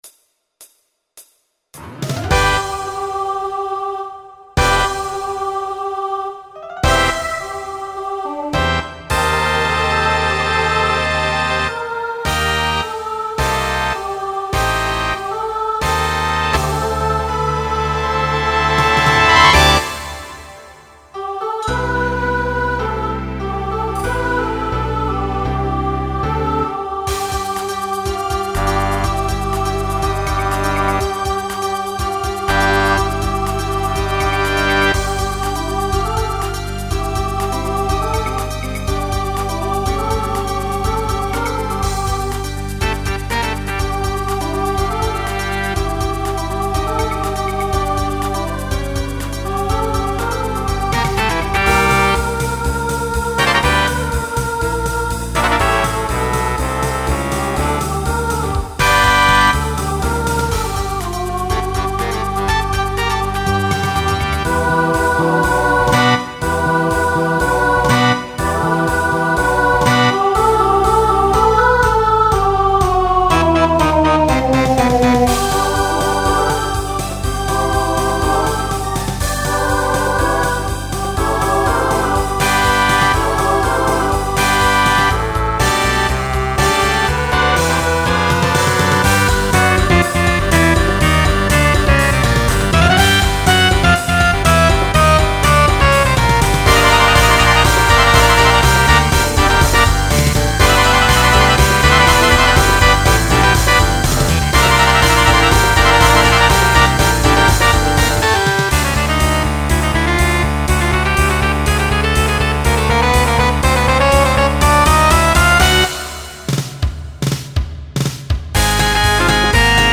Genre Broadway/Film
Show Function 2nd Number , Solo Feature Voicing SSA